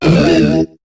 Cri de Tarinor dans Pokémon HOME.